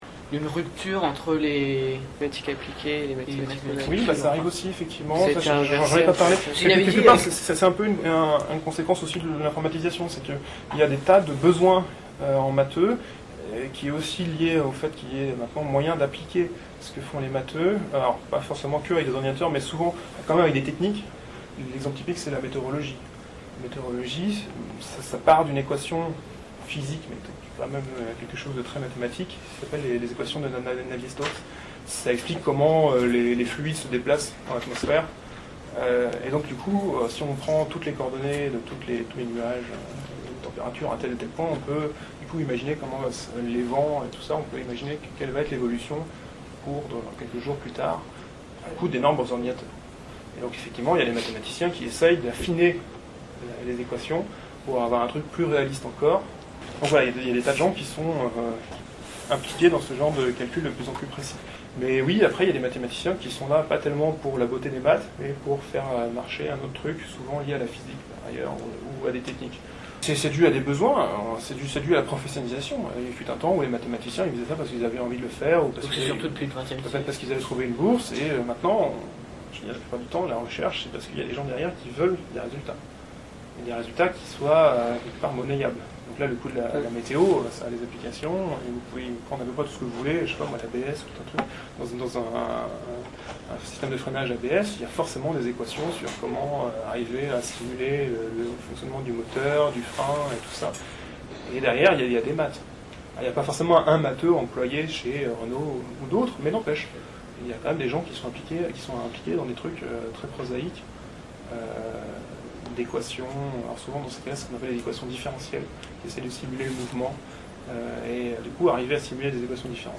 Interview (Rupture entre mathématiques pures et appliquées)